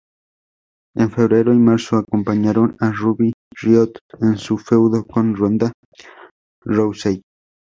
/feˈbɾeɾo/